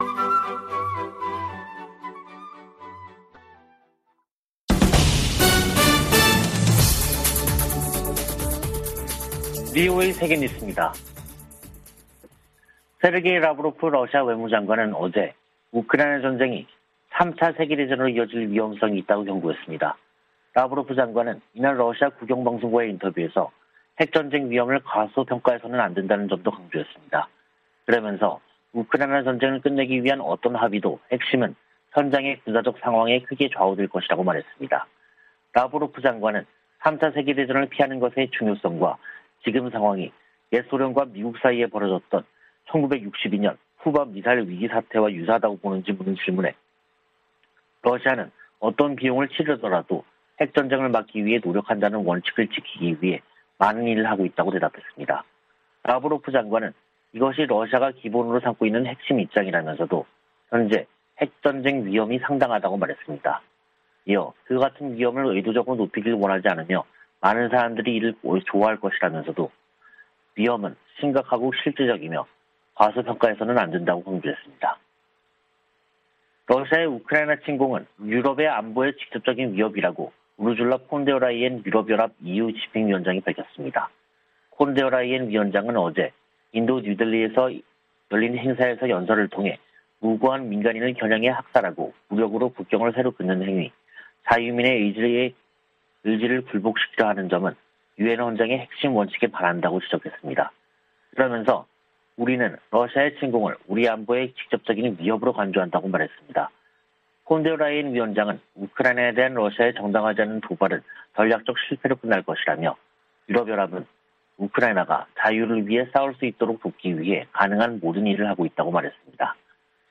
VOA 한국어 간판 뉴스 프로그램 '뉴스 투데이', 2022년 4월 26일 3부 방송입니다. 북한이 25일 핵 무력을 과시하는 열병식을 개최했습니다.